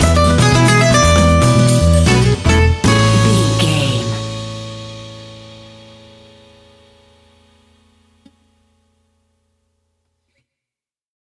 Ionian/Major
romantic
maracas
percussion spanish guitar